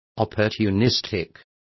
Complete with pronunciation of the translation of opportunistic.